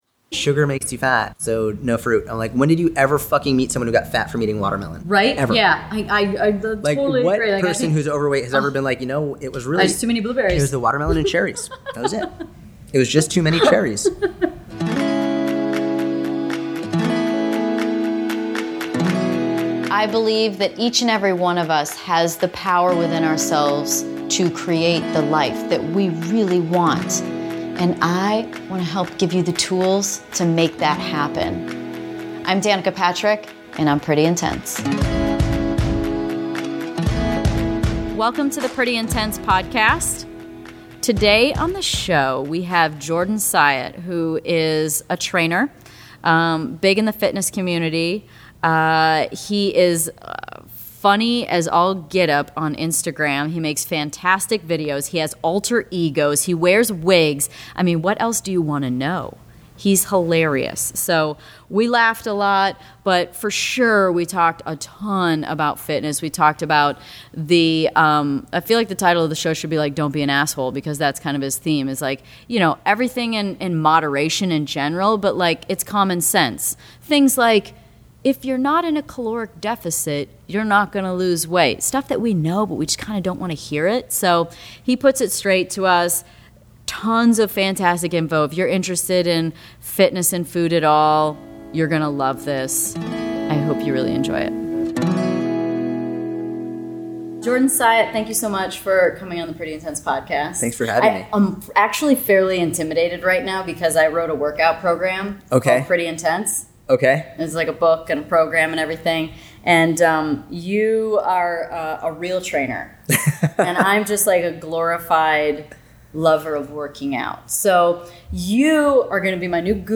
So we laughed a lot. But for sure, we talked a ton about fitness.